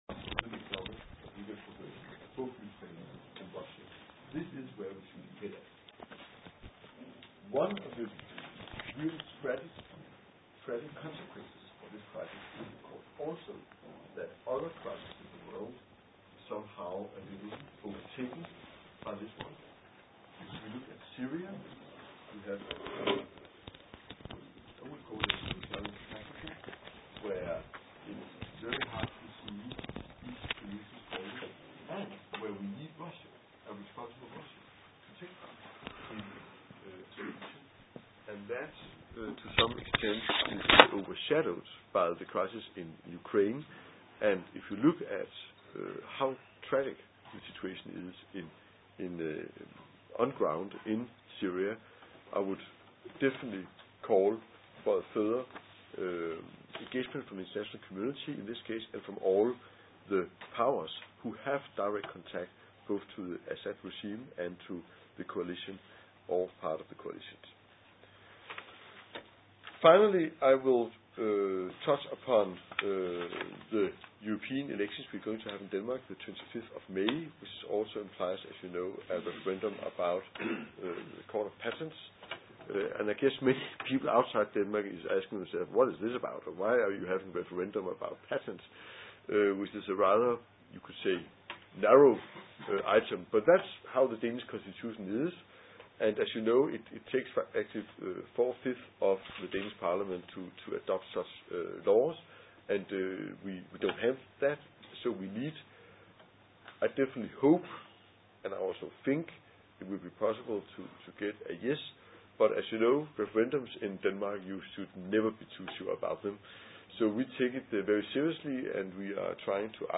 på hans første pressekonference for internationale journalister på Udenrigsministeriets Internationale Pressecenter. Lidegaard svarede ved at nedspille neo-nazisternes rolle og benægte faren for atomkrig.